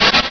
Cri de Ningale dans Pokémon Rubis et Saphir.
Cri_0290_RS.ogg